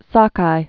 (säkī)